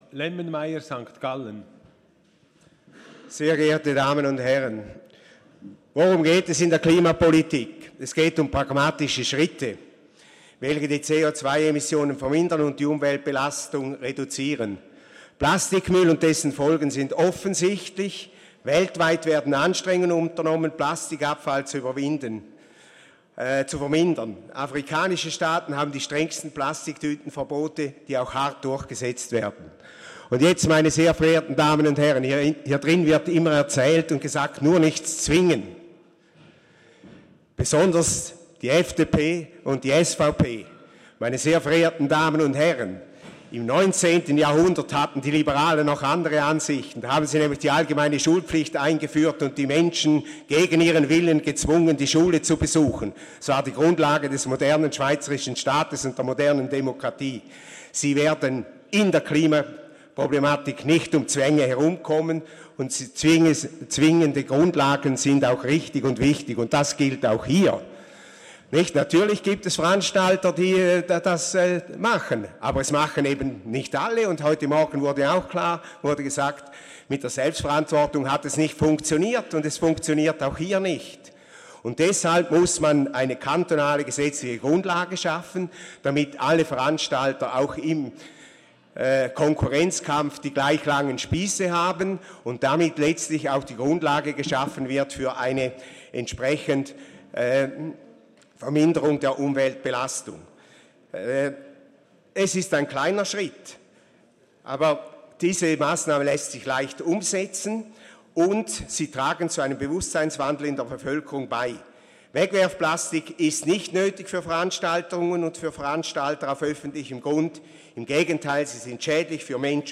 Session des Kantonsrates vom 11. bis 13. Juni 2019